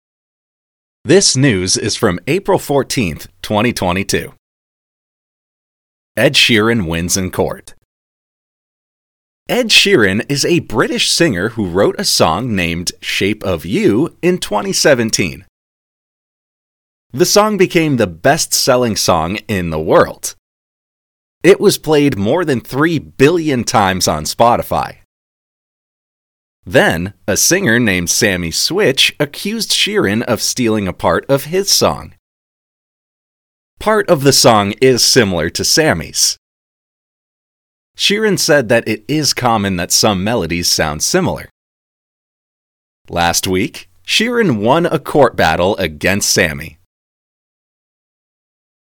Shadowing